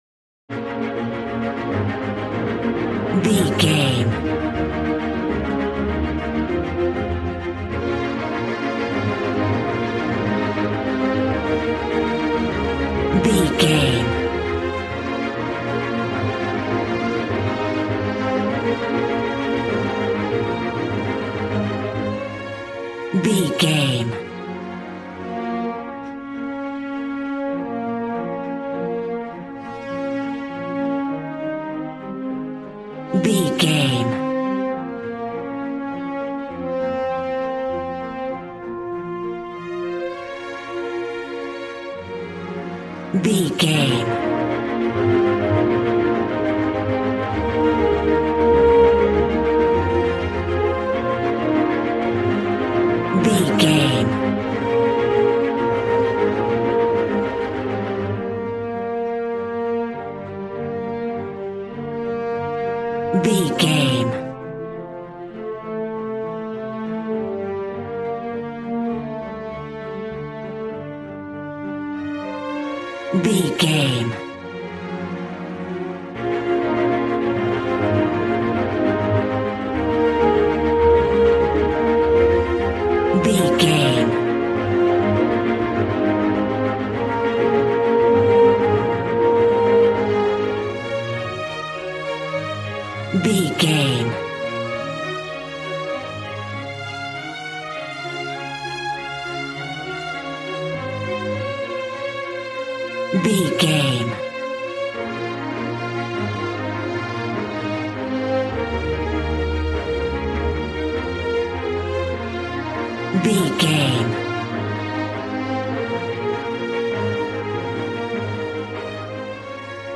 Regal and romantic, a classy piece of classical music.
Aeolian/Minor
B♭
regal
cello
violin
strings